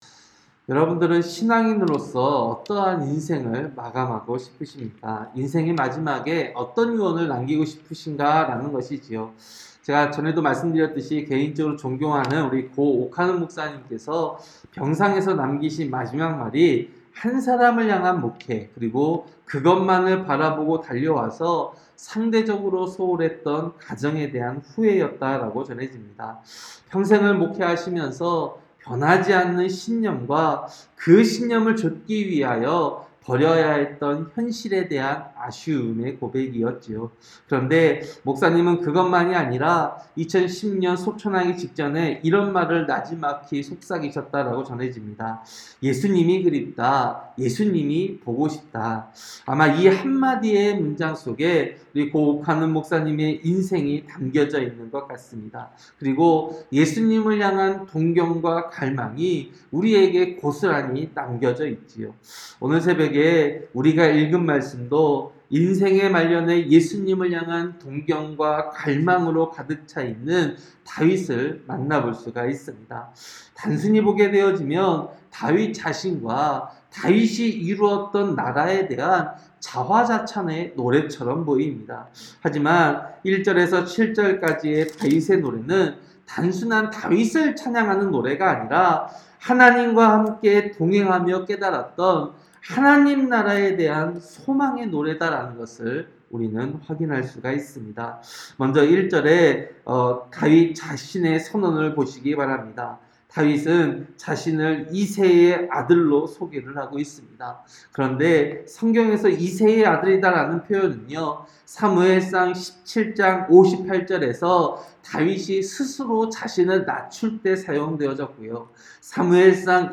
새벽설교-사무엘하 23장